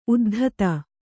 ♪ uddhata